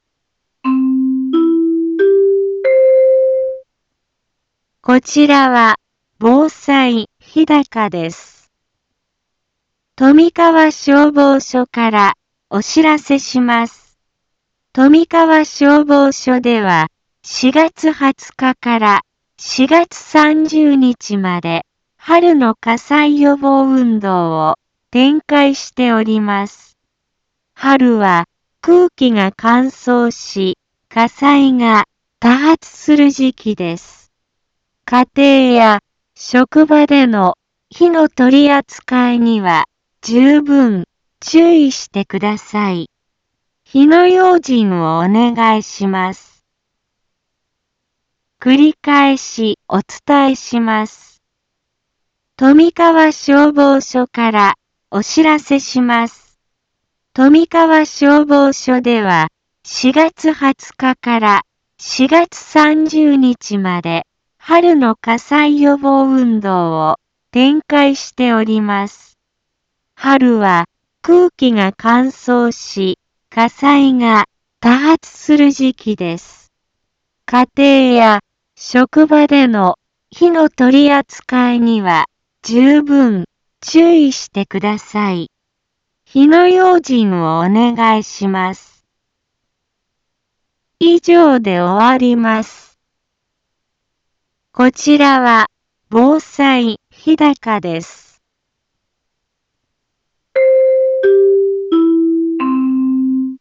Back Home 一般放送情報 音声放送 再生 一般放送情報 登録日時：2020-04-21 15:03:39 タイトル：春の火災予防運動について インフォメーション： こちらは、防災日高です。